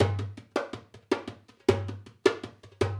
Dumbek 10.wav